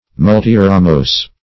\Mul`ti*ra*mose"\